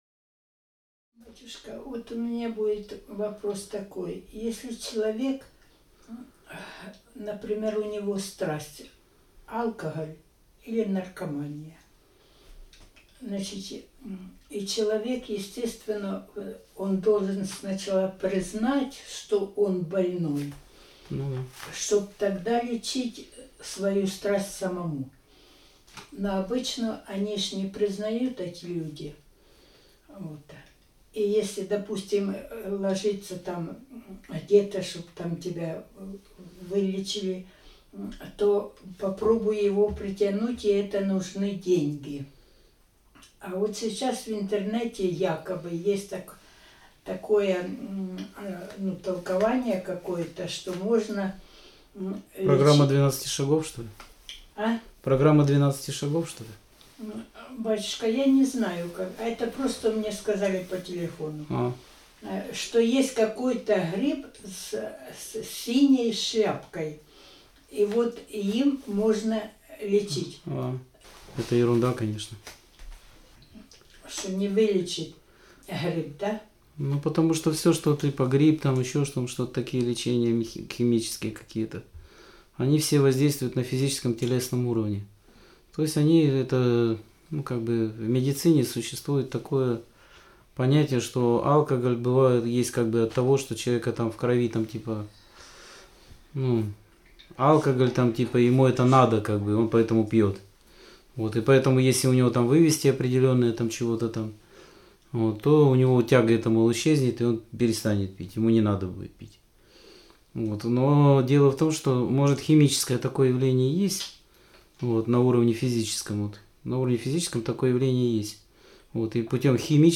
Скайп-беседа 25.01.2018